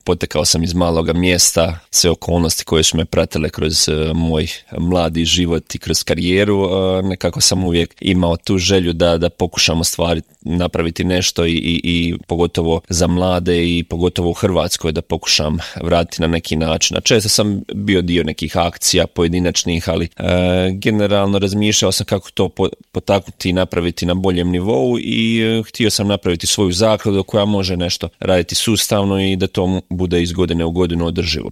Kako je došao na ideju osnivanja zaklade, Marin je u intervjuu Media servisa rekao: